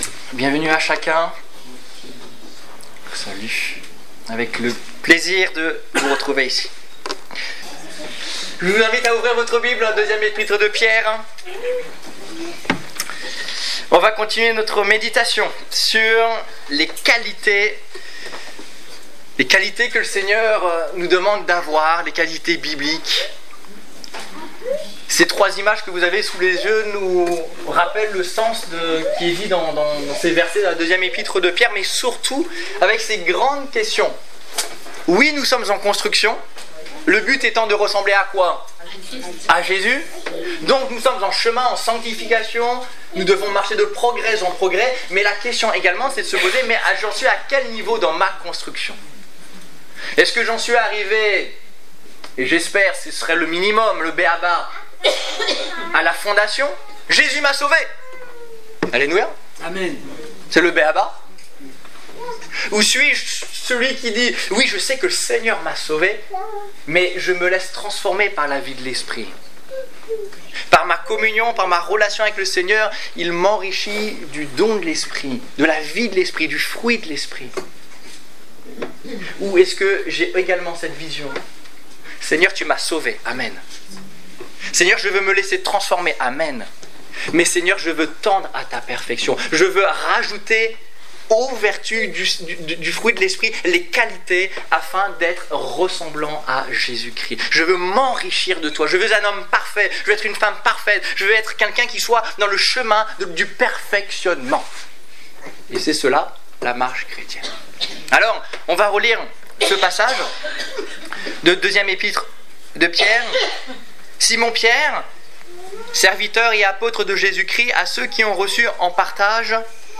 Quelques qualités bibliques - La patience Détails Prédications - liste complète Culte du 18 octobre 2015 Ecoutez l'enregistrement de ce message à l'aide du lecteur